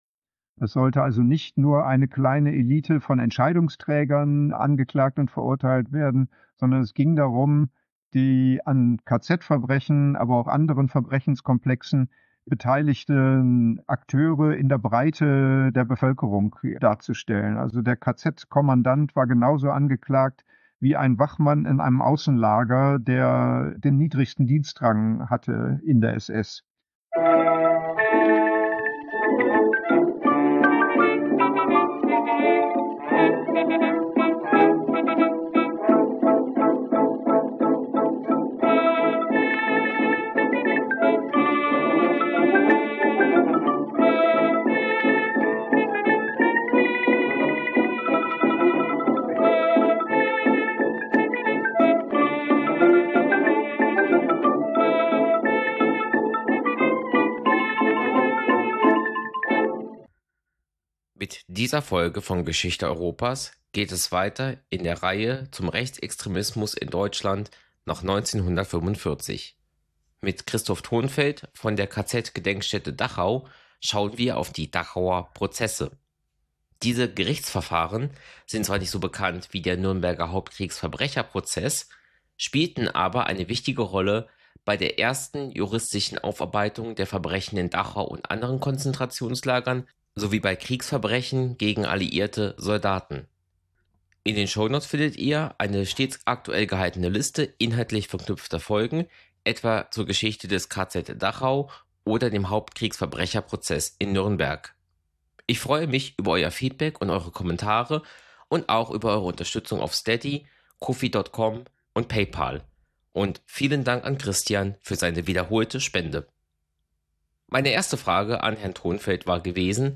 Ein Podcast über die Geschichte Europas. Gespräche mit Expert:innen, angefangen beim geologischen Entstehen der europäischen Halbinsel bis hin zum heutigen Tag.